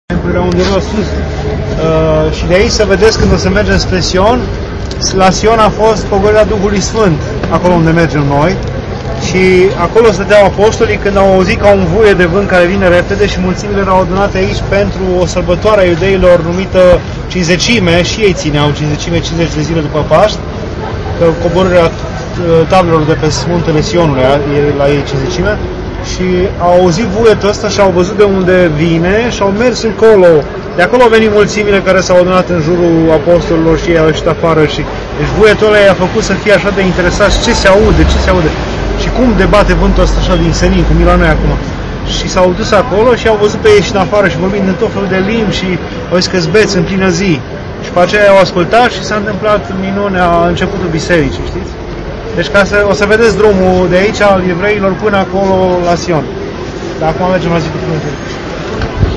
Ierusalim, despre Sion și Cincizecime